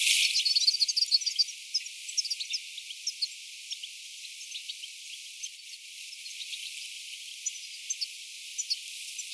Niiden ääntely on hentoa.
Ja vähän varovaista.
nuoria tikleja 12 parvi
ilmeisesti_nuoria_tikleja_12_parvi.mp3